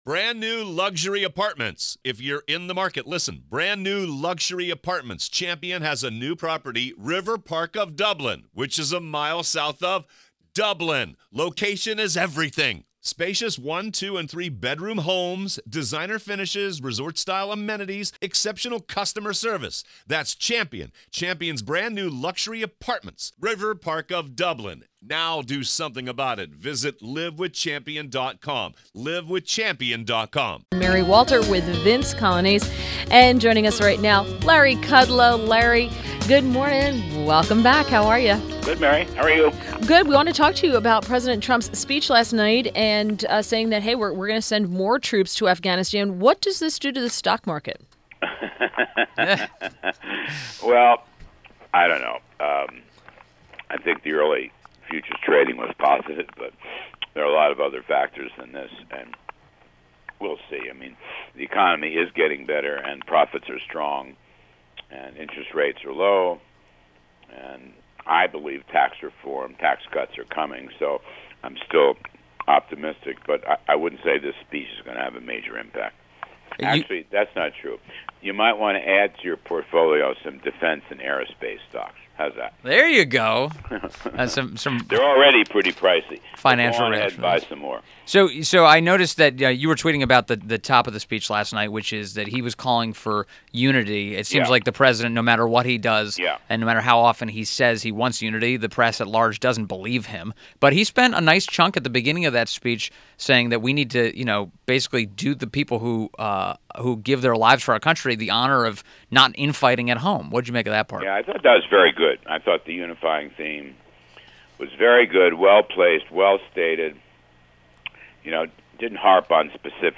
WMAL Interview - LARRY KUDLOW - 08.22.17